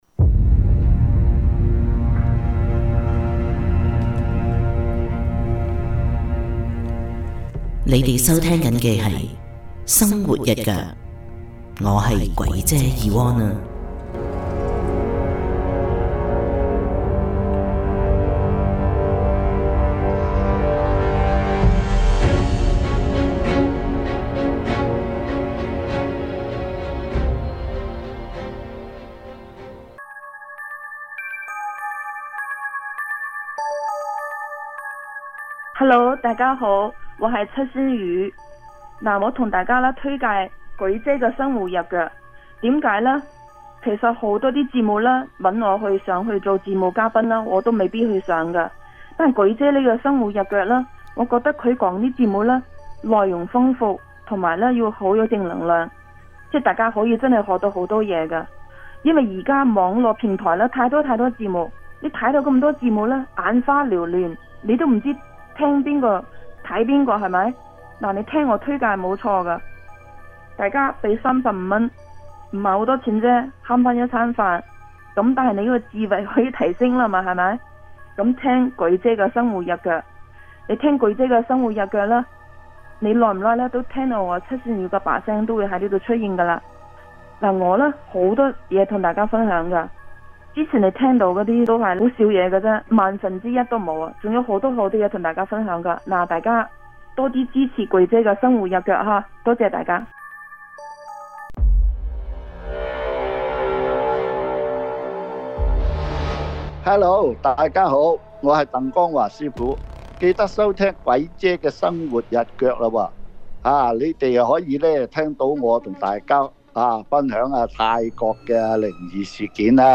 但今次錄這訪問搞到我有點疲累。
就今次出街這聲音也有少許問題。